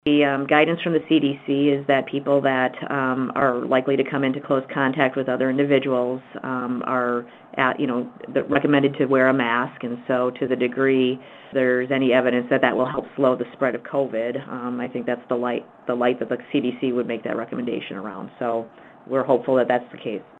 Some large retail chains– including Walmart and Menards– are now requiring customers to wear a mask when they go inside the store to shop. Malsam-Rysdon says the requirement is helpful.